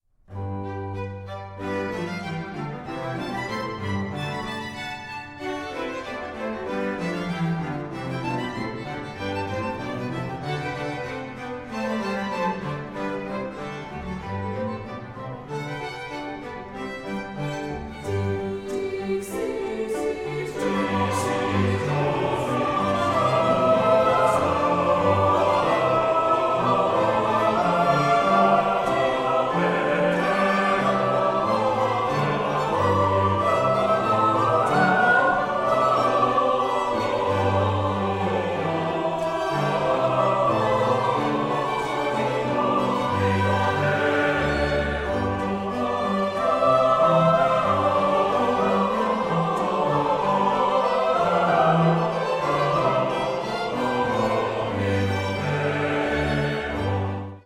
Stereo
for five soloists, choir and strings